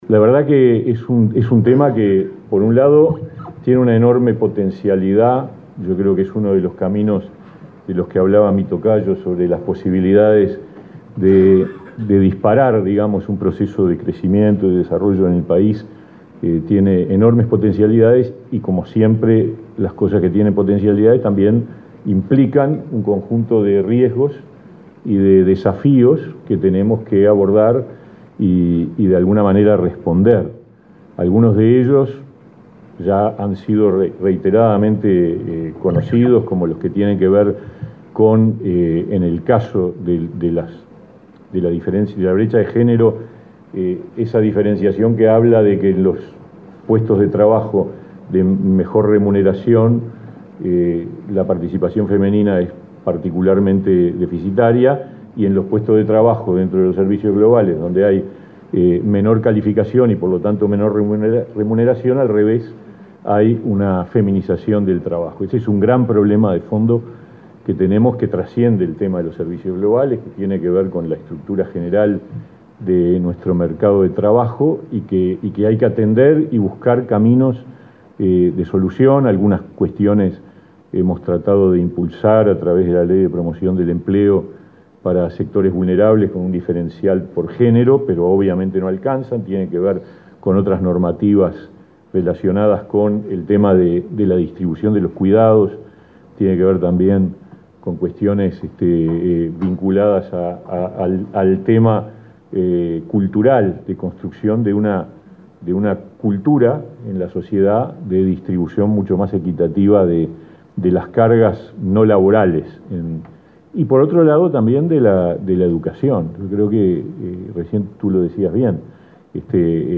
Palabras del ministro de Trabajo, Pablo Mieres
El Ministerio de Trabajo y Seguridad Social, la Organización de las Naciones Unidas en Uruguay y la Oficina de la Organización Internacional del Trabajo (OIT) para el Cono Sur de América Latina presentaron este viernes 11 en Montevideo el informe “Evolución y desafíos del sector servicios globales en Uruguay: condiciones laborales, brechas de género y orientaciones de política”. El ministro Pablo Mieres señaló la importancia del documento.